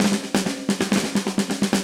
Index of /musicradar/80s-heat-samples/130bpm
AM_MiliSnareC_130-03.wav